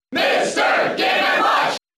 Mr. Game & Watch's cheer in the US versions of Brawl.
Mr._Game_&_Watch_Cheer_English_SSBB.ogg